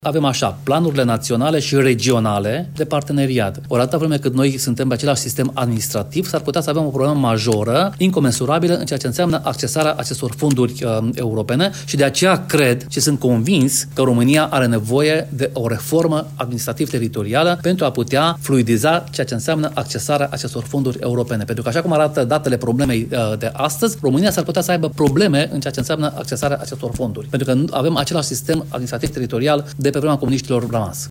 Eurodeputatul român Daniel Buda cu precizări privind necesitatea unei reforme administrativ teritoriale în România, odată cu introducerea pentru perioada 2028 -2034 a planurilor de parteneriat naționale și regionale.